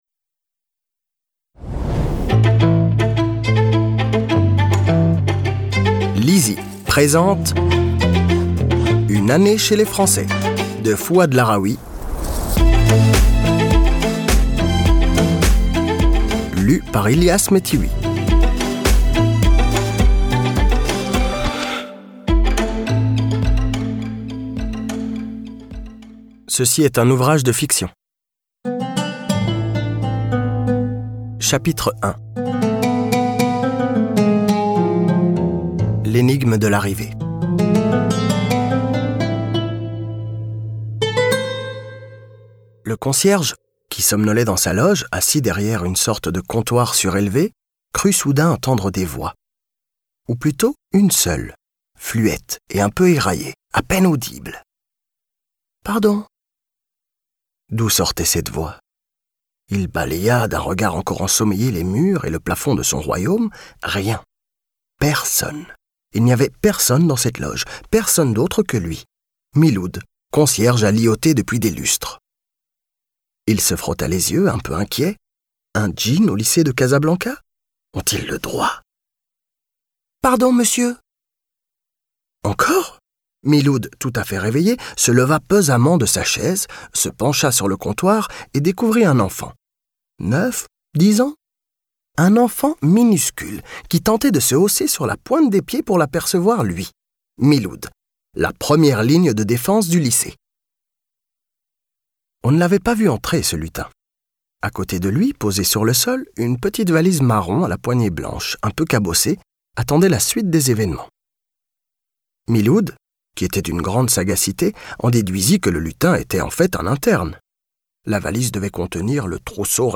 Click for an excerpt - Une année chez les français de Fouad LAROUI